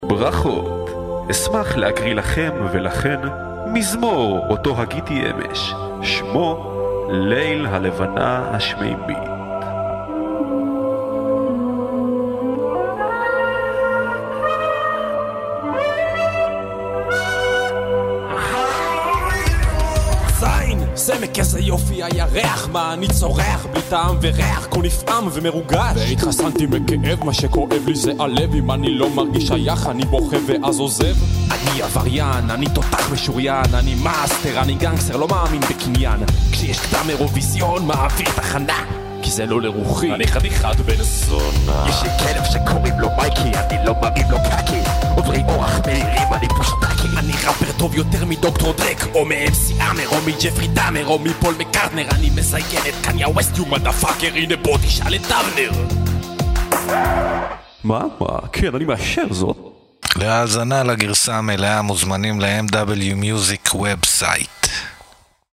כולם עם הפקה חיה, טקסט מדויק וגישה ישירה.